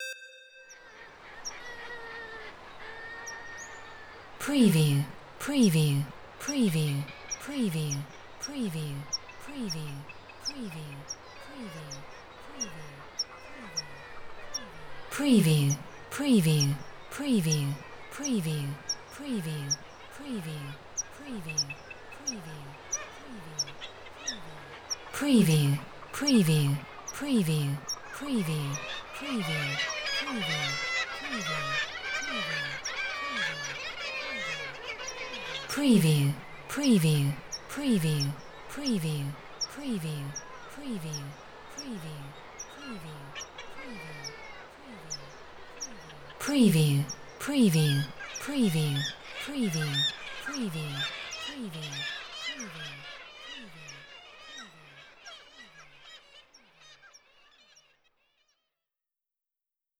Sea Birds Kittiwake Nesting
Stereo sound effect - Wav.16 bit/44.1 KHz and Mp3 128 Kbps
previewANM_BIRD_KITTIWAKE_NESTING_WBHD02.wav